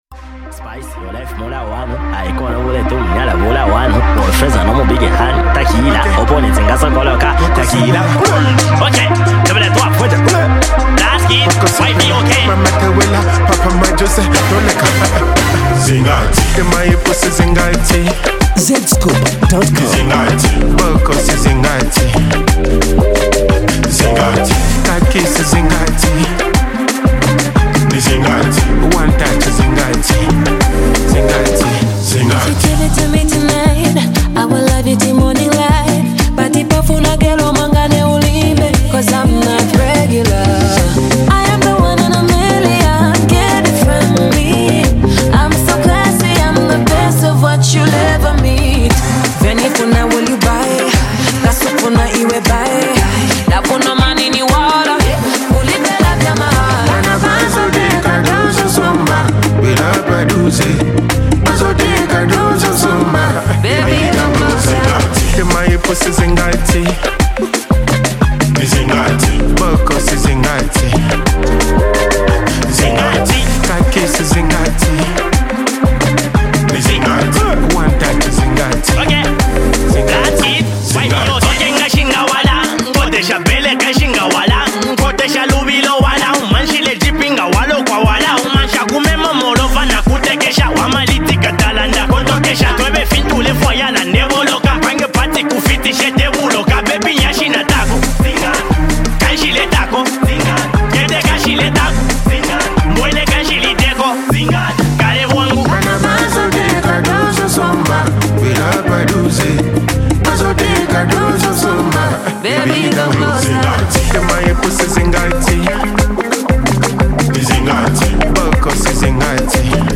a club favorite